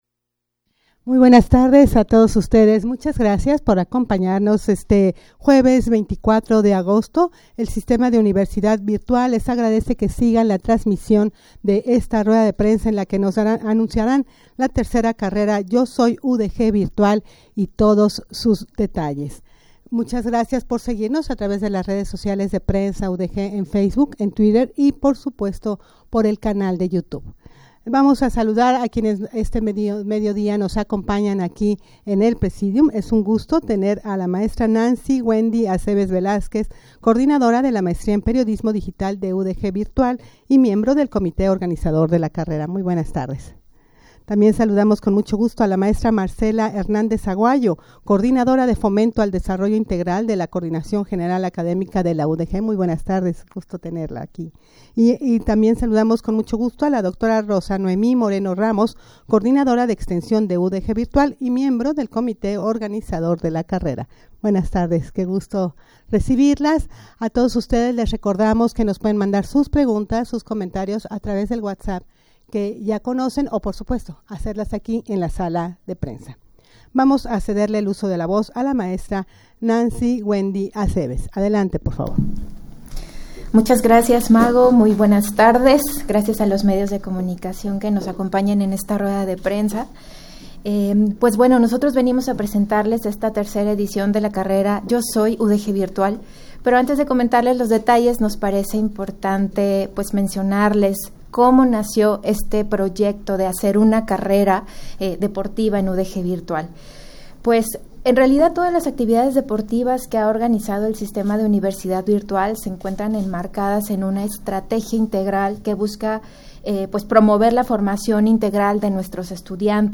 rueda-de-prensa-para-anunciar-la-tercera-carrera-yo-soy-udgvirtual.mp3